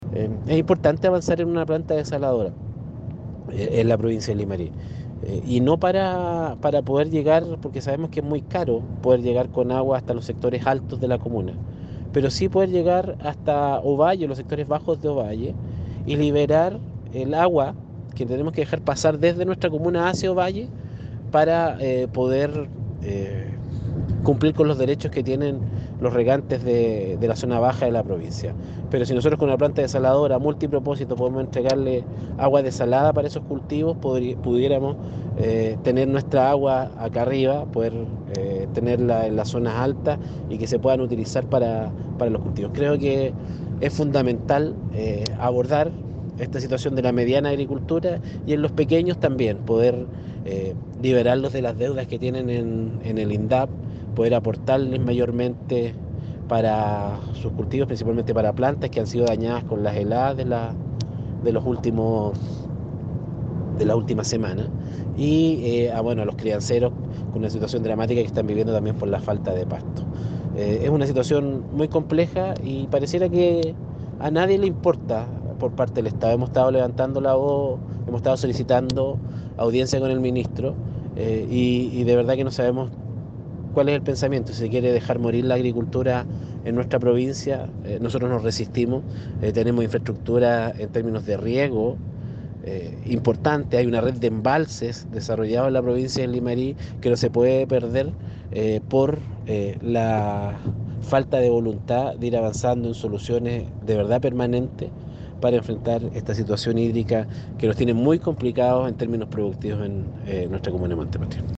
ALCALDE-HERRERA-POR-CRISIS-ECONOMICA_2-Cristian-Herrera-Pena.mp3